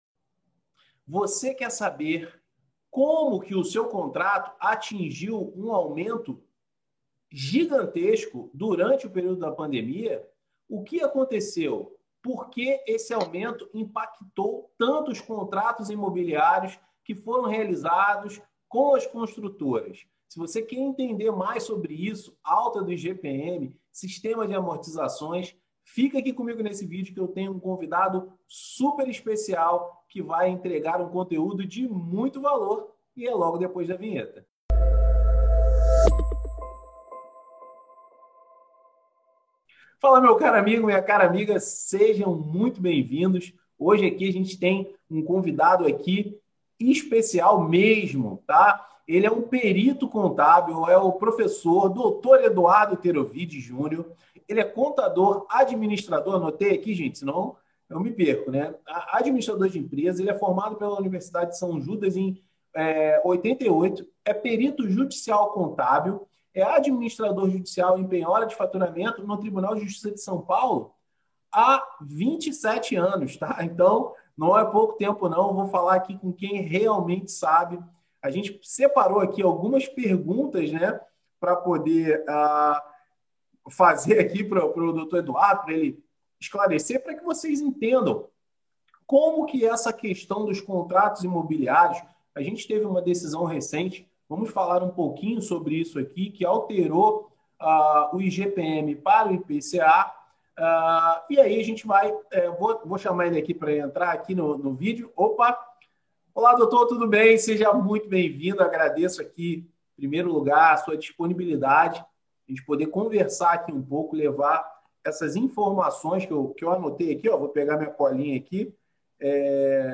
Financiamento de imóvel direto com a construtora pode ser um verdadeiro pesadelo, nesse vídeo eu e o Perito Contábil expert em contratos de financiamento falamos bastante sobre as abusividades cometidas pelas construtoras e também como é possível o consumidor se proteger desse...